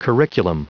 Prononciation du mot curriculum en anglais (fichier audio)
Prononciation du mot : curriculum